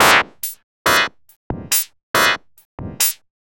tx_synth_140_clusterchop1.wav